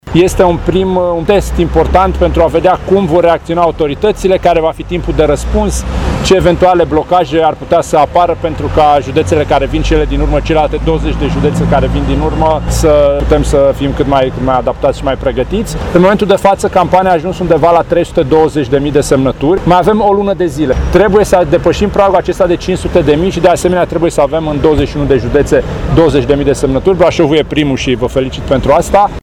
Președintele USR, Dan Barna, a participat la Brașov, la depunerea listelor.